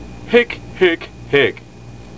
With brown spots and zig-zaggy lines on its back, this species flies off low to the ground and calls out
hick-hick-hick, etc...